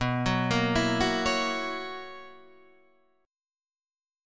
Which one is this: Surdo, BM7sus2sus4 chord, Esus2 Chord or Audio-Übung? BM7sus2sus4 chord